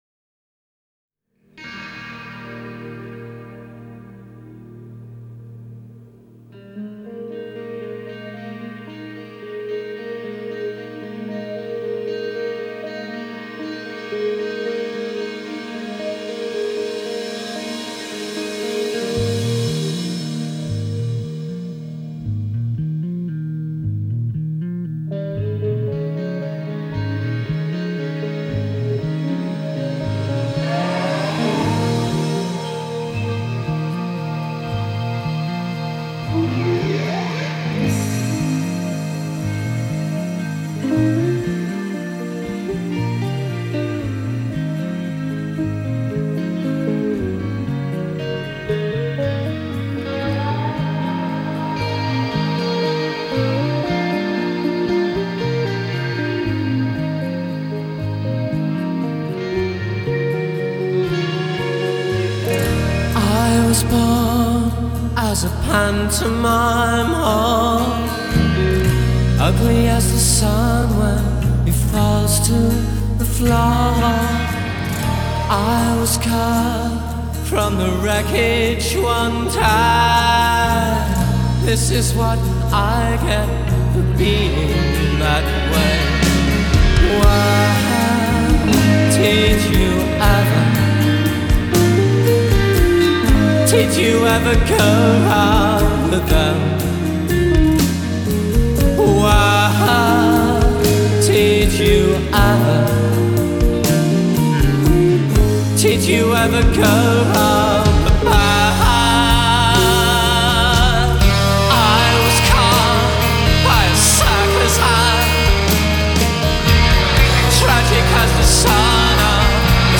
Style: Alt Rock